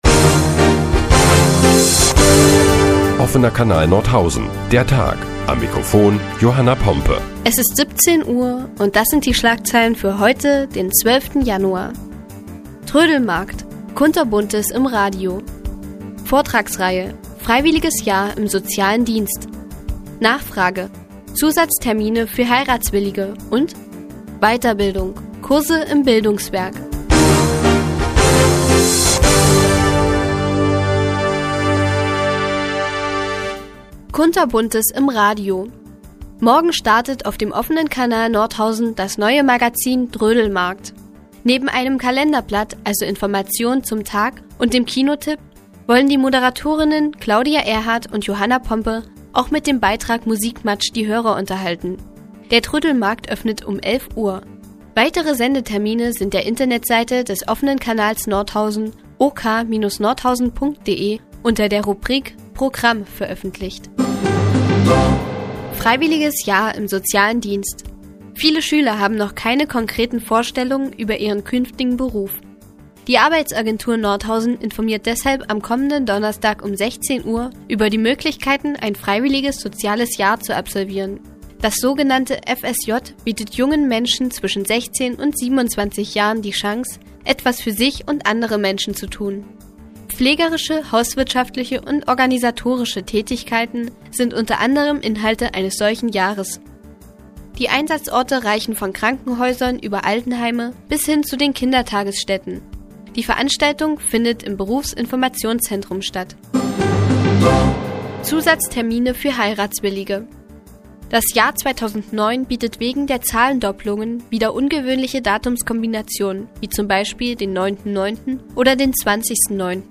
Die tägliche Nachrichtensendung des OKN ist nun auch in der nnz zu hören. Heute geht es unter anderem um Kurse im Bildungswerk und um Zusatztermine für Heiratswillige.